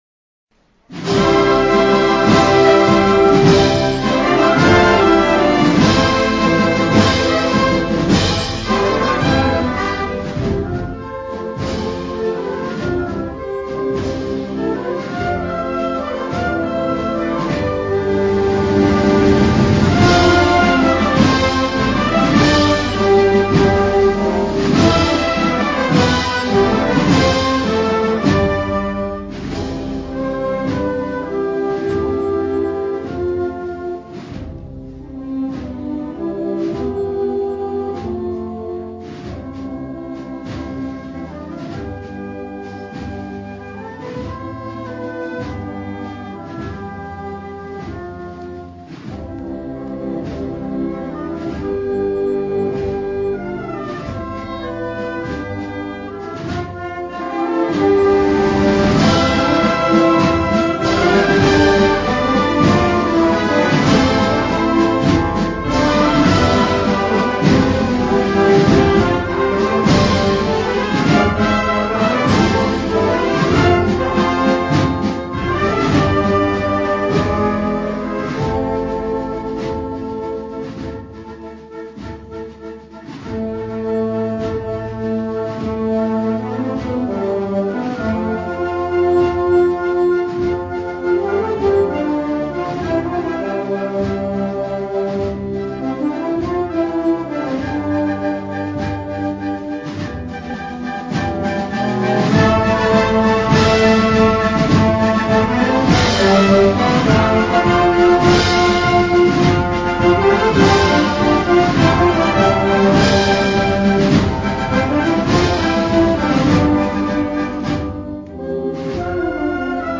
Marchas dedicadas a María Santísima del Auxilio